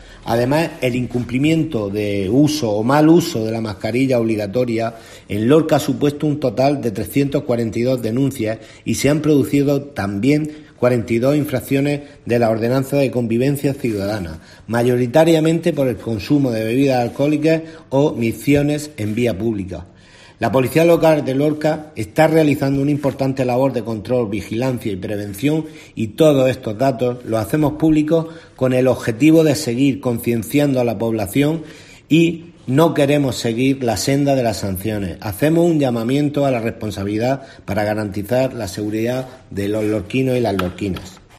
José Luis Ruiz, edil Seguridad